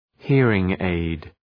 {‘hırıŋ,eıd}